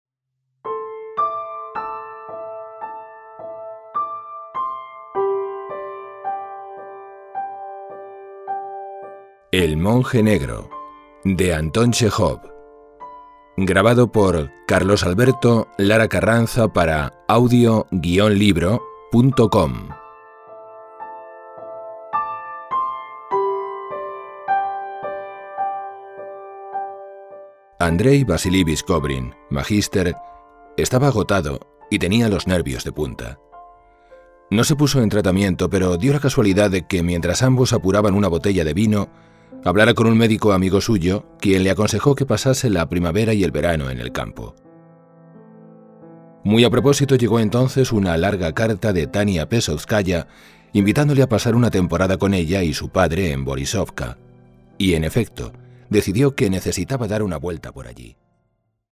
El monje negro : Audiolibr
Música: Doc & Zero-Project (cc:by-sa)